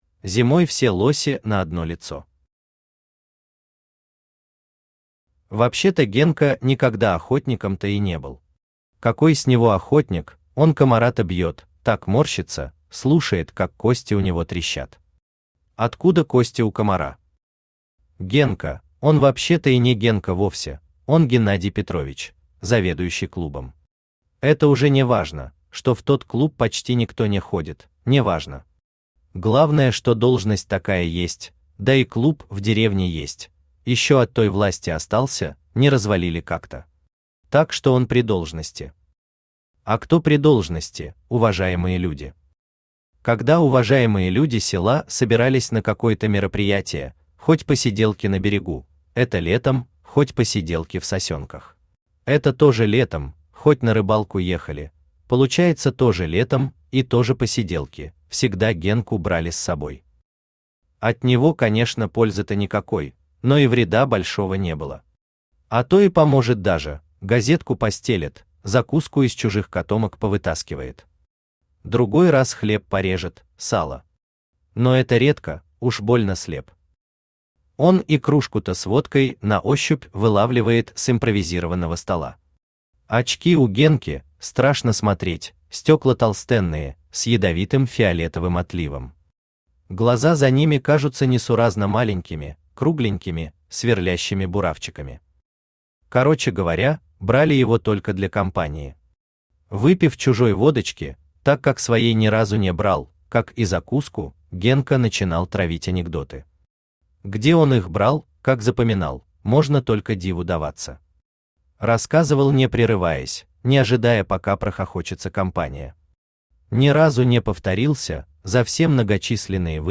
Аудиокнига Охотничьи страсти | Библиотека аудиокниг
Aудиокнига Охотничьи страсти Автор Андрей Андреевич Томилов Читает аудиокнигу Авточтец ЛитРес.